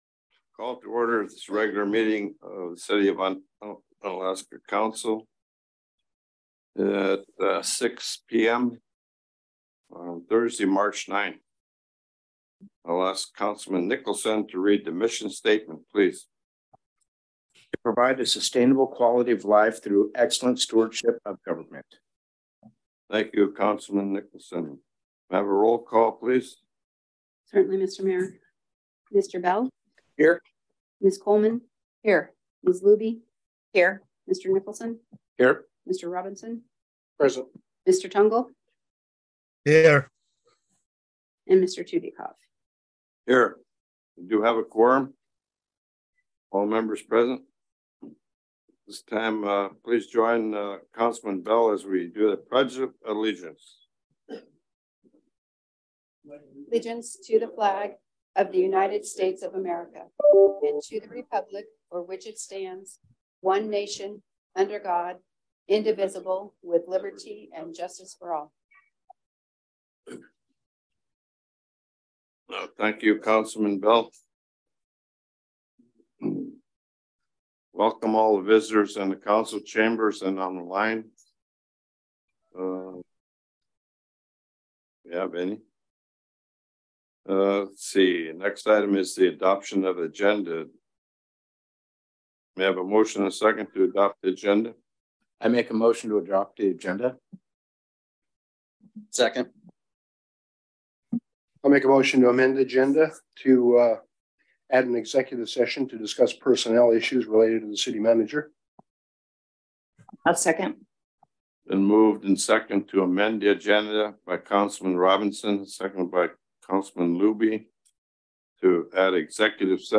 City Council Meeting - March 9, 2023 | City of Unalaska - International Port of Dutch Harbor
In person at City Hall (43 Raven Way)